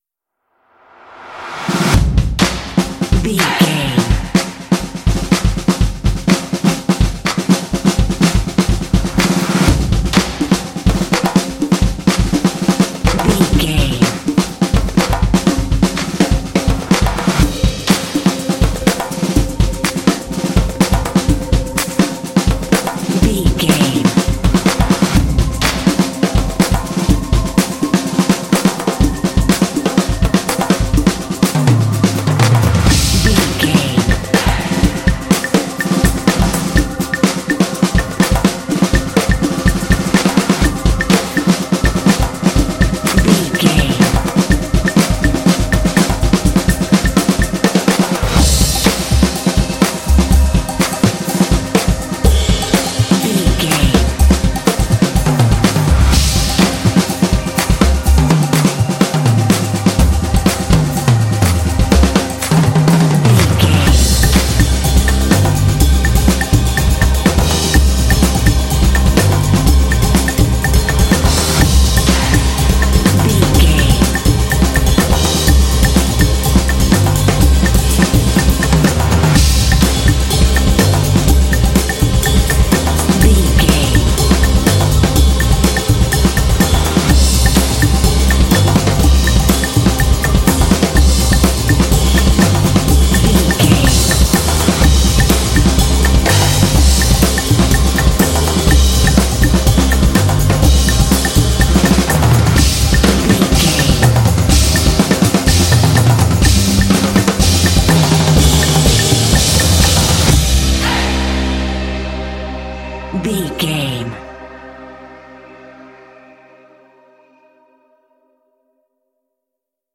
This epic drumline will pump you up for some intense action.
Epic / Action
Atonal
driving
motivational
drums
percussion
vocals
bass guitar
synthesiser